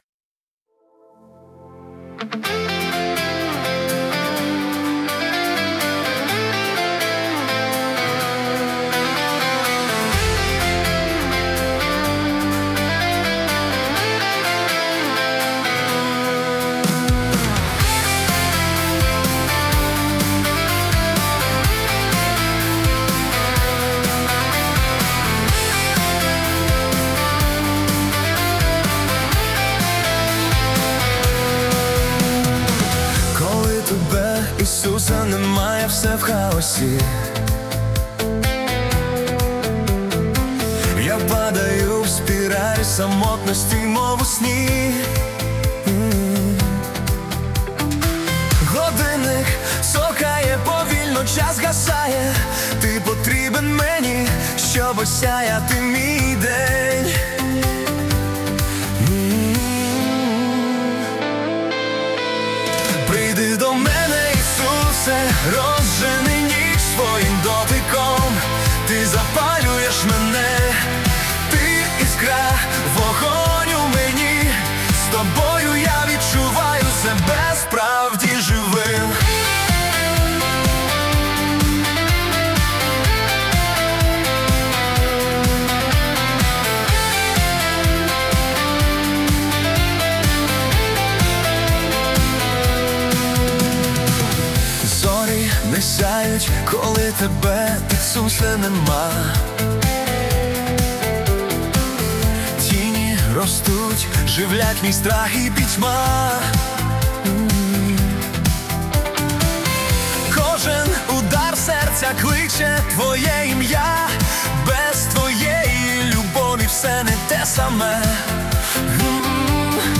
песня ai
27 просмотров 57 прослушиваний 3 скачивания BPM: 120